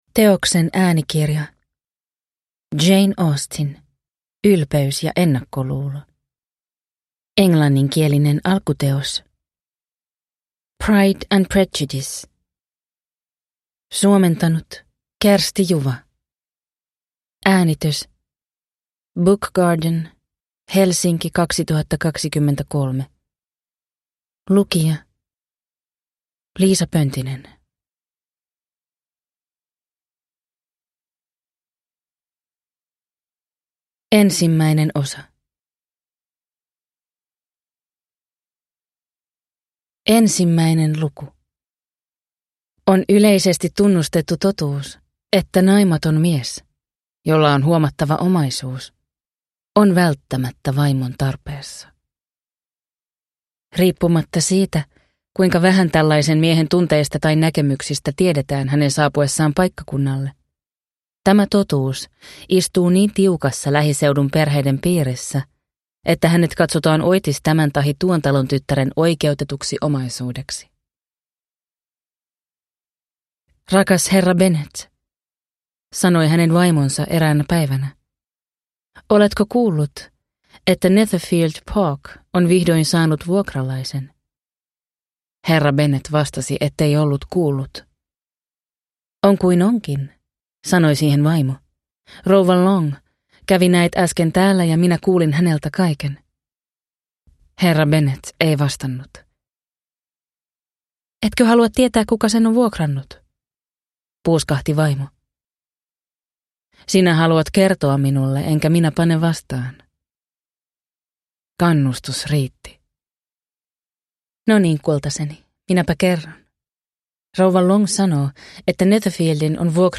Ylpeys ja ennakkoluulo – Ljudbok